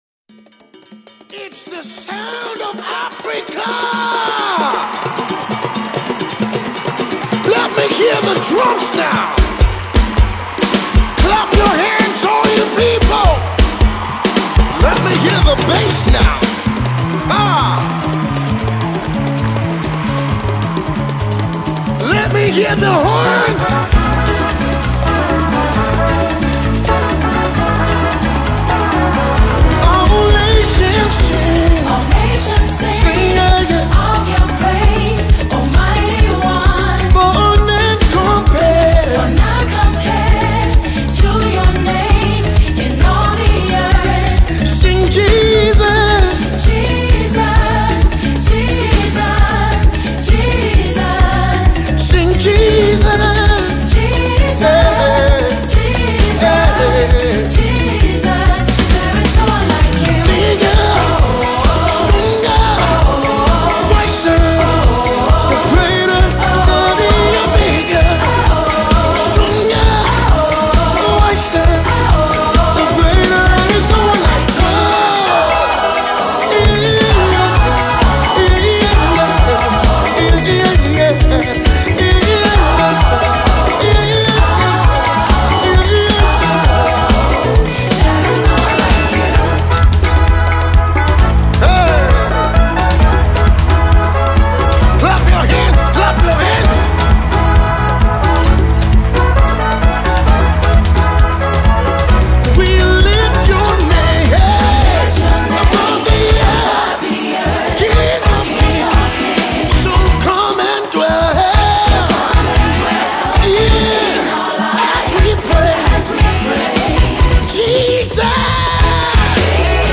a Global Gospel singer & songwriter